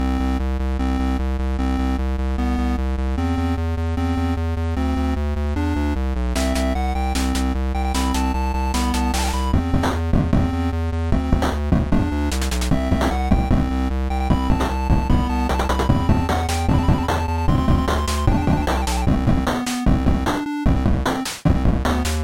蜂鸣器 " 蜂鸣器 8 - 声音 - 淘声网 - 免费音效素材资源|视频游戏配乐下载
用Beep Box创建的鼓舞人心的快乐芯片循环。